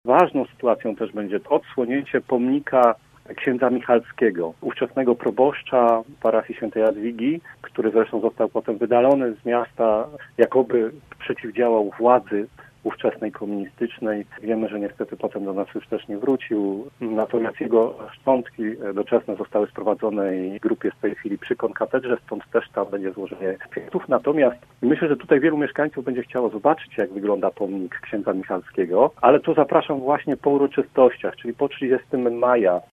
Mówi Piotr Barczak, przewodniczący Rady Miasta z PiS: